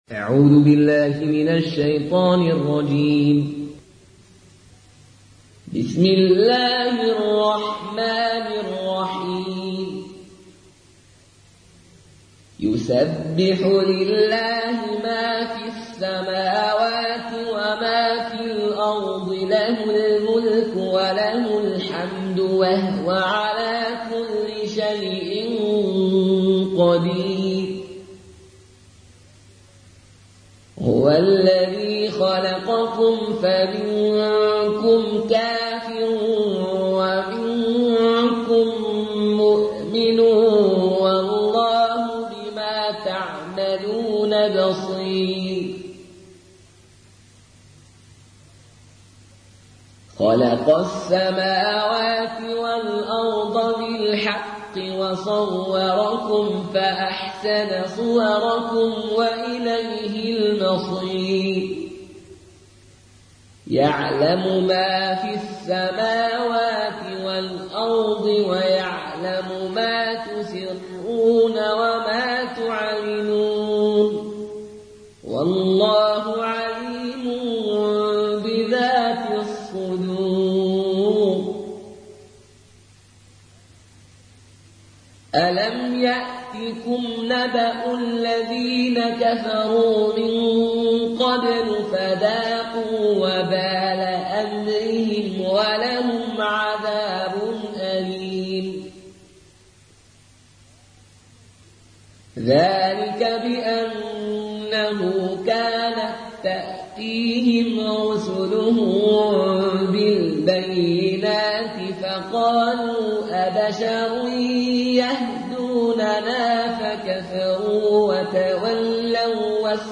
(روایت فالون)